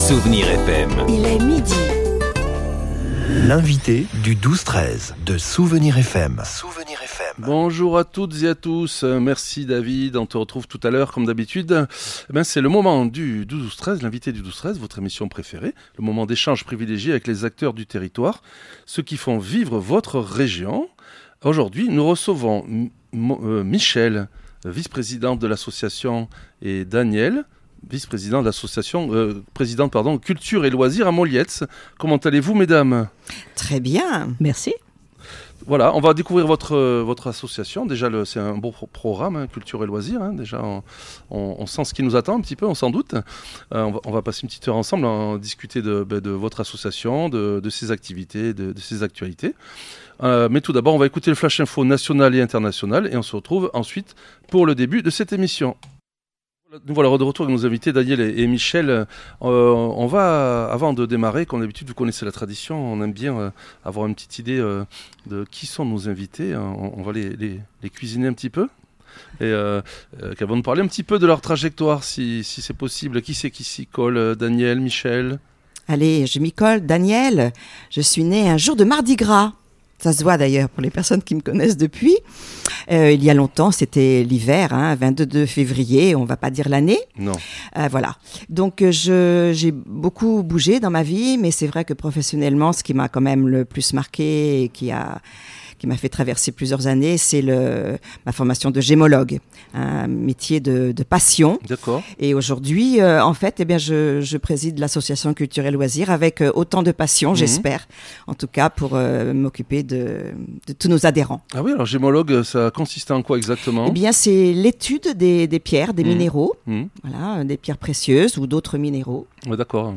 Avec une cinquantaine d’adhérents, cette association cultive la bonne humeur et la légèreté – et ça s’entend !